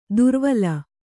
♪ durvala